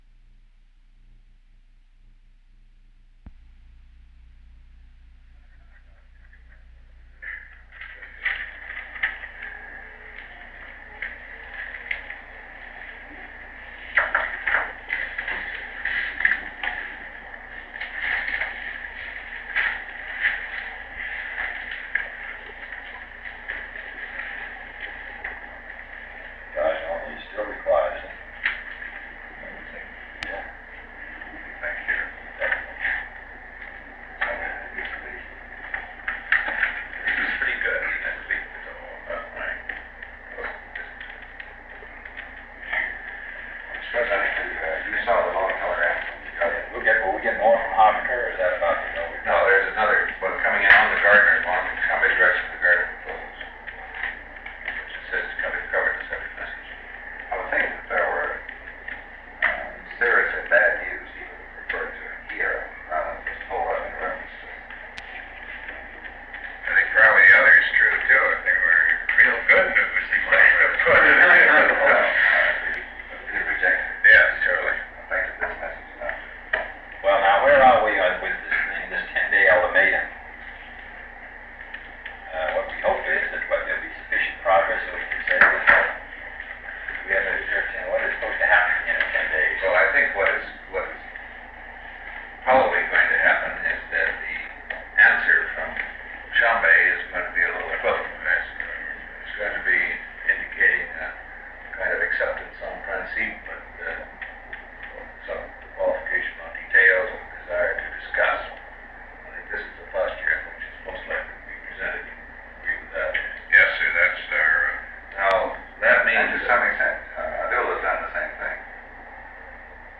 Secret White House Tapes | John F. Kennedy Presidency Meeting on the Congo Rewind 10 seconds Play/Pause Fast-forward 10 seconds 0:00 Download audio Previous Meetings: Tape 121/A57.